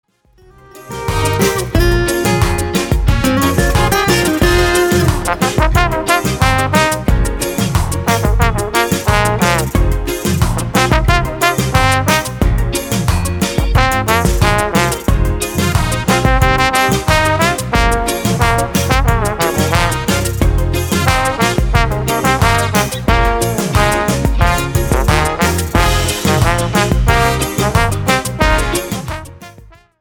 POP  (03.11)